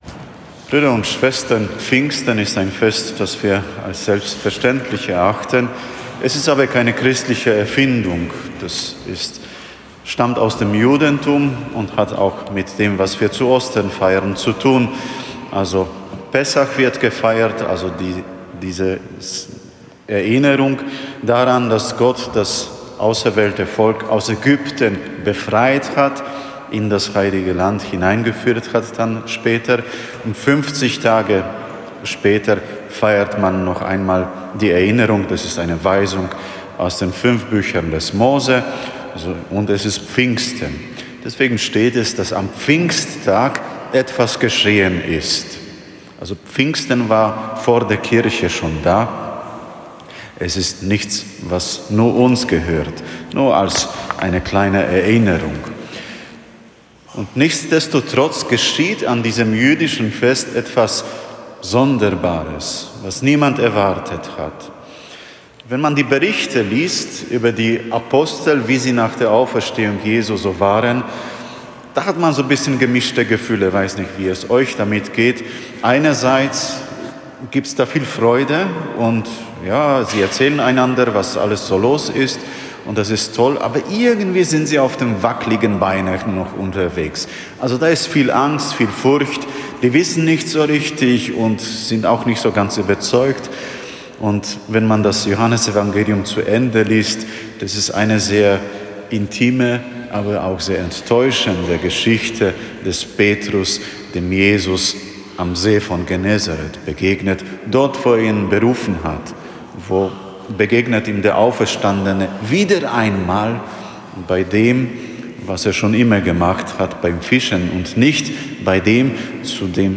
Eine Predigt zum hohen Pfingstfest im Lesehar B